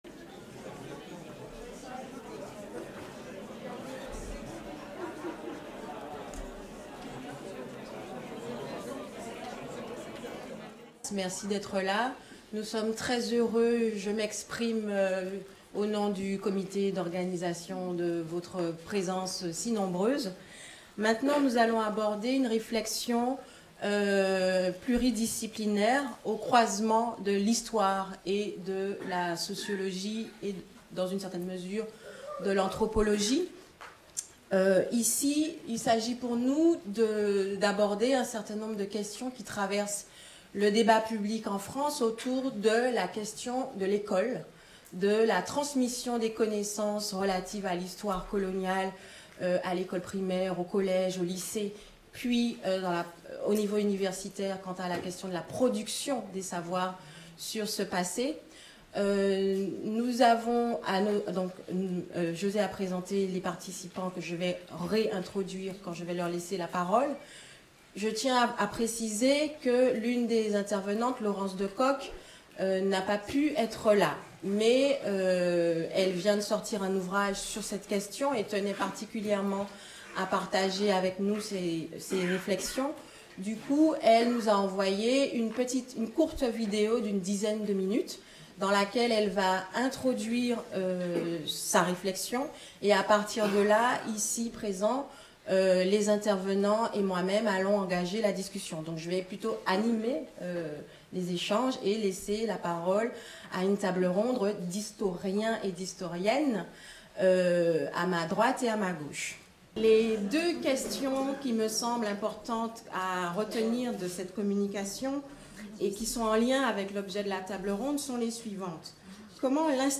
4. Table-ronde : De l’école et de l’université coloniales à la situation contemporaine, quels transferts et/ou héritages ?